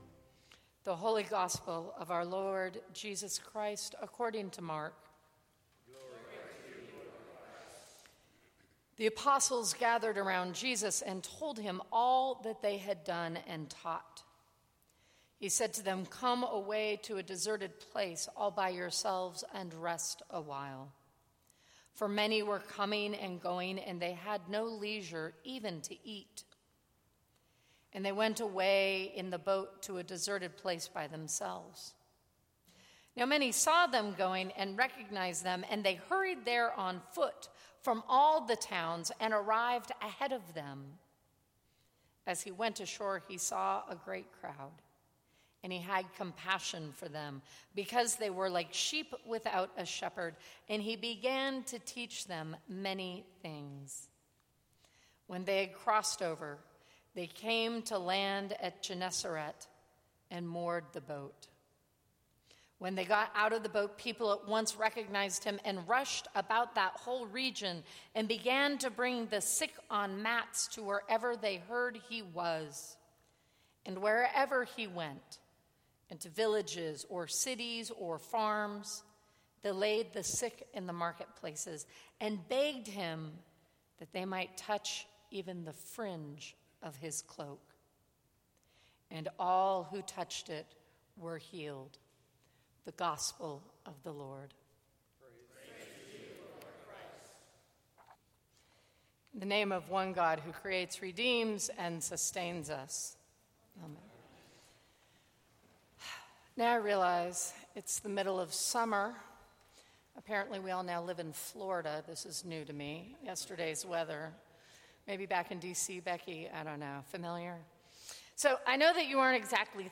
Sermons from St. Cross Episcopal Church What is the most important thing to being a Christian?